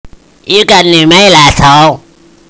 New mail notification - Cartman type voice lets you know you have new E-mail!
newmail.wav